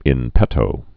(ĭn pĕtō)